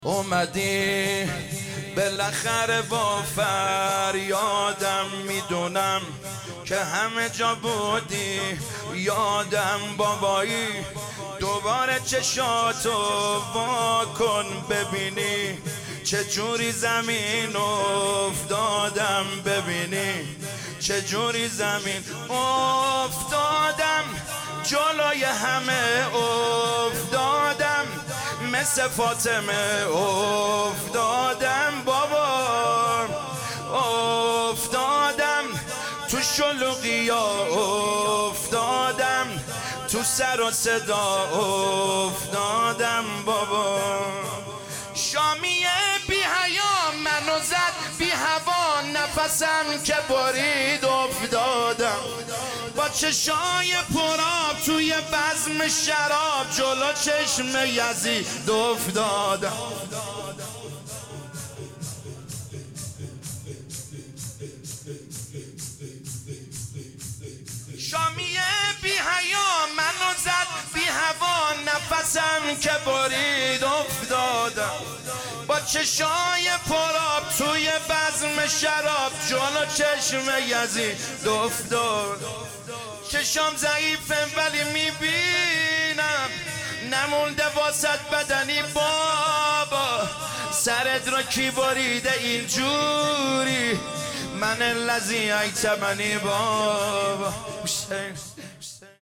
شور
شب سوم محرم 1399